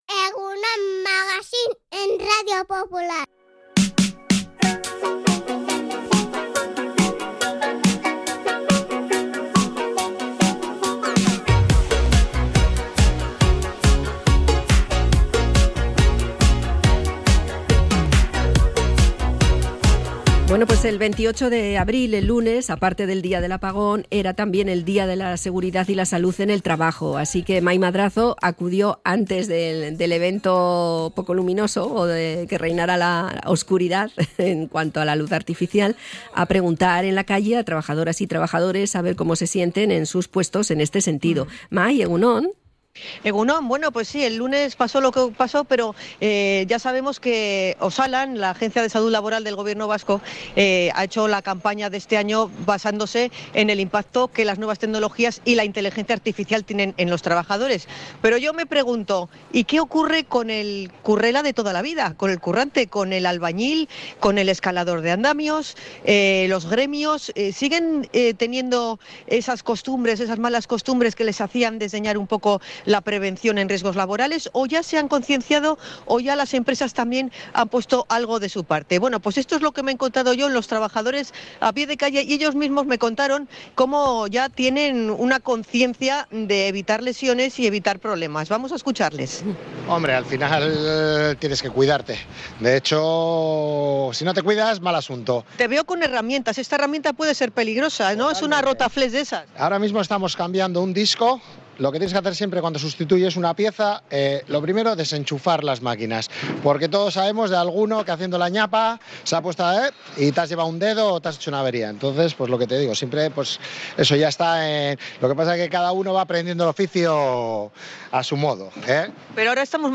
Hablamos con trabajadores de distintos gremios
Salimos a la calle por el Día Internacional de la Salud y Seguridad en el Trabajo
REPORTAJE-SALUD-LABORAL.mp3